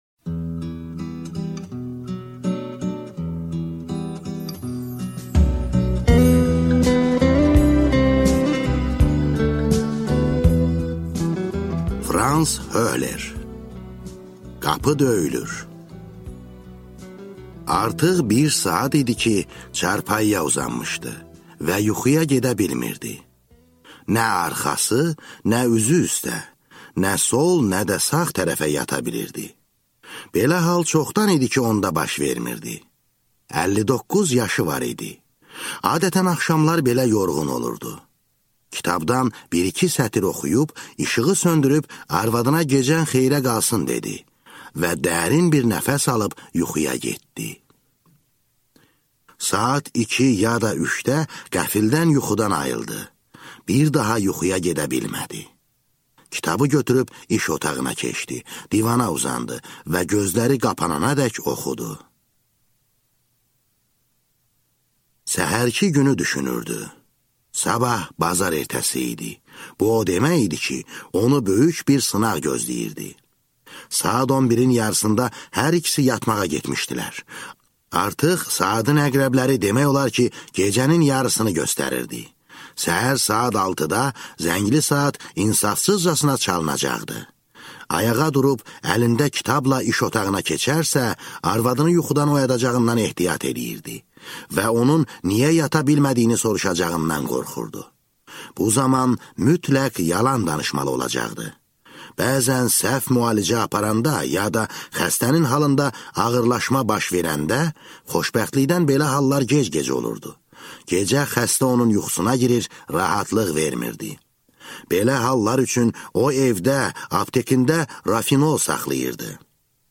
Аудиокнига Qapı döyülür | Библиотека аудиокниг